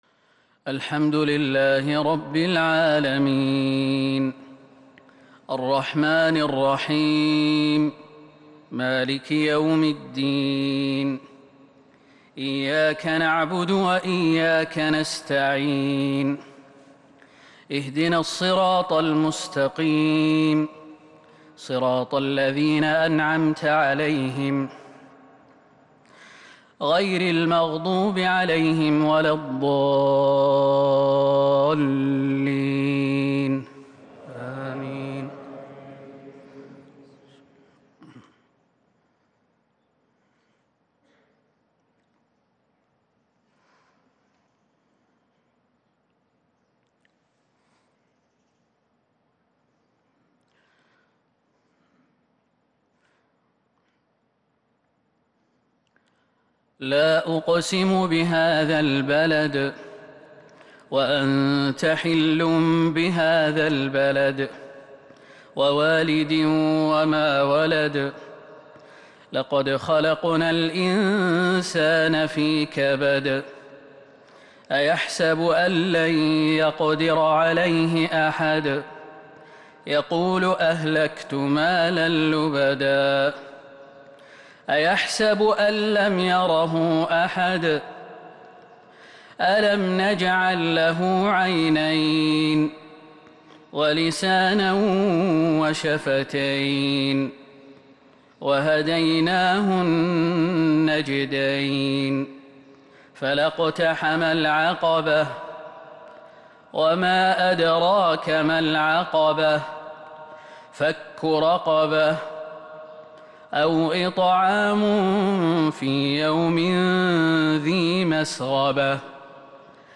فجر الأحد 8-8-1442هـ من سورة البلد والليل | Fajr prayer from surat Al-Balad and Al-Layl 21/3/2021 > 1442 🕌 > الفروض - تلاوات الحرمين